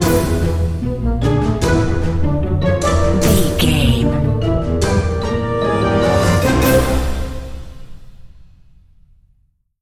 Uplifting
Lydian
flute
oboe
strings
cello
double bass
percussion
silly
goofy
comical
cheerful
perky
Light hearted
quirky